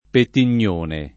[ pettin’n’ 1 ne ]